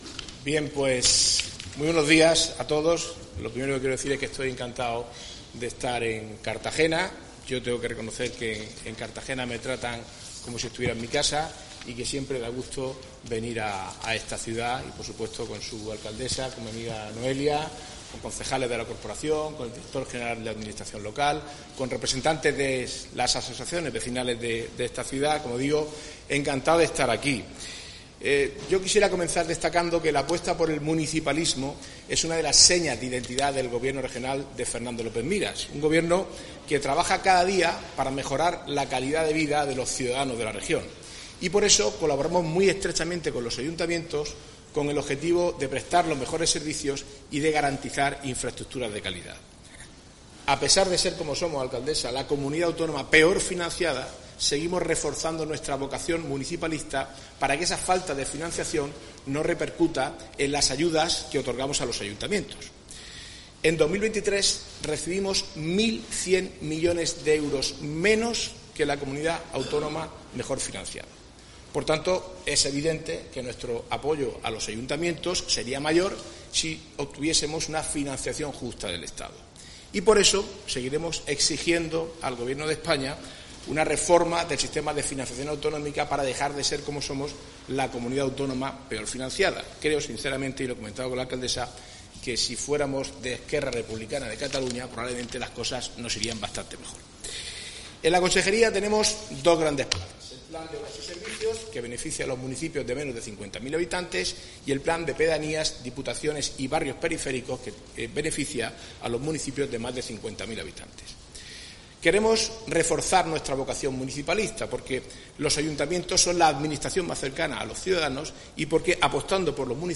Enlace a Declaraciones de Marcos Ortuño y Noelia Arroyo.